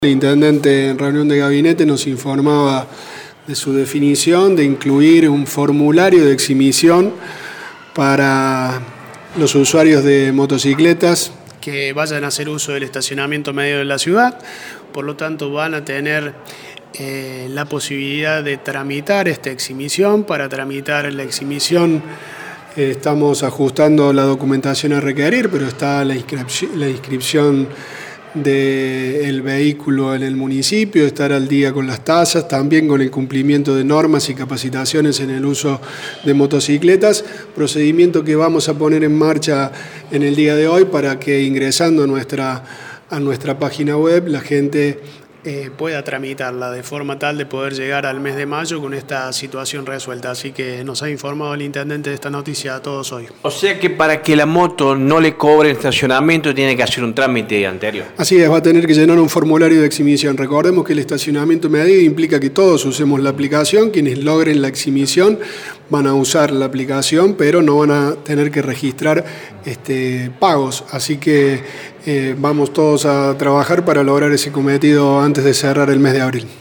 Pablo Rosso, subsecretario de Sistemas y Transformación Digital, brindó más detalles.